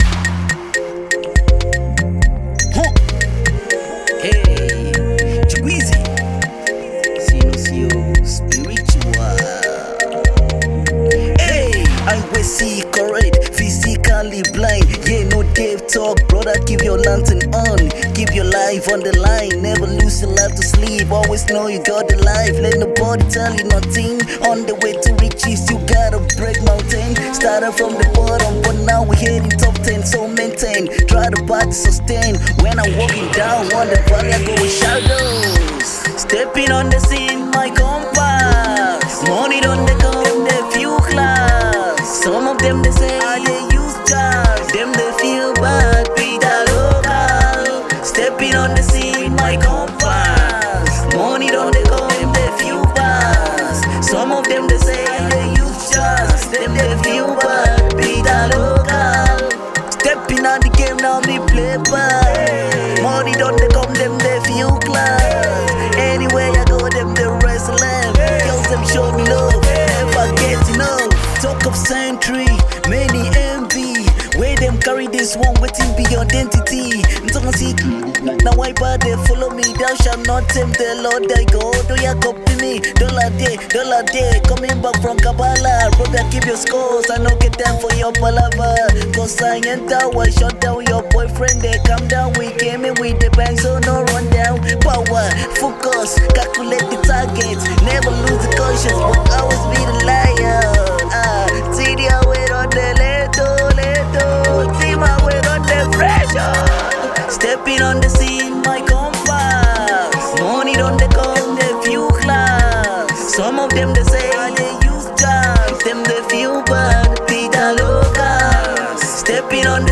Ogene Rap
irresistible feel
the controversial rapper reigned fire flows to spice it up.